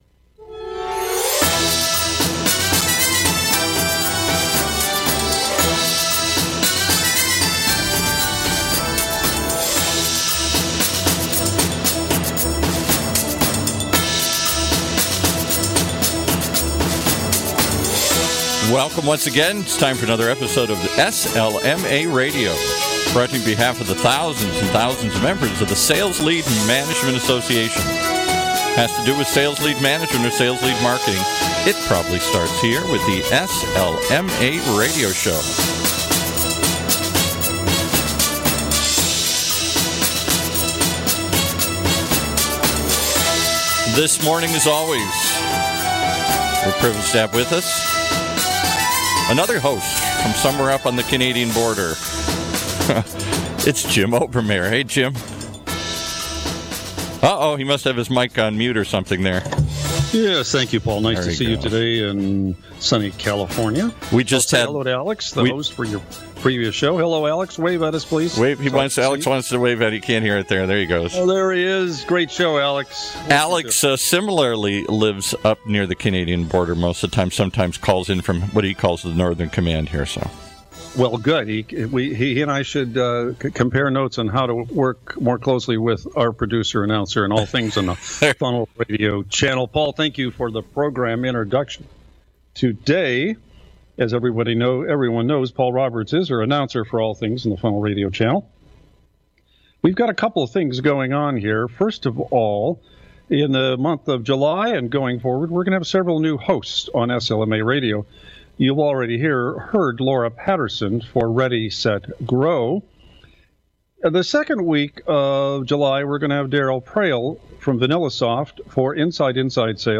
But is the professed ignorance a ploy by marketers that simply don’t know how to prove that the content they create is worth the expense? In this interview